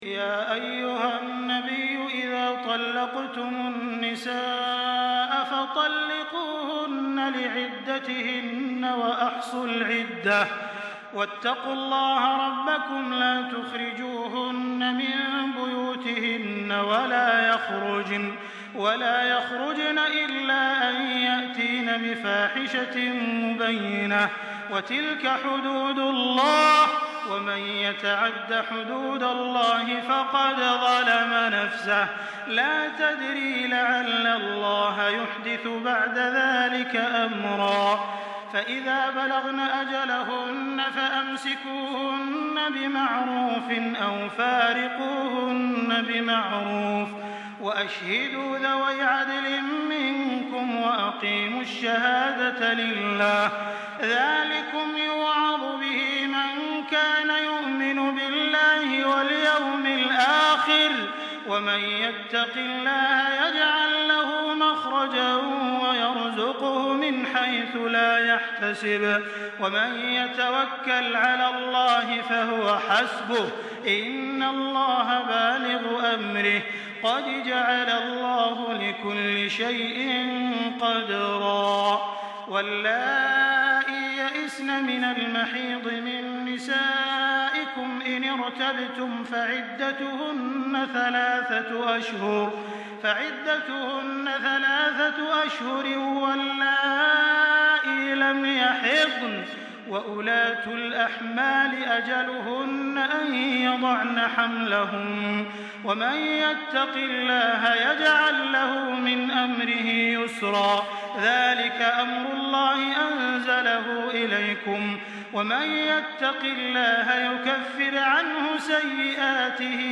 دانلود سوره الطلاق توسط تراويح الحرم المكي 1424
مرتل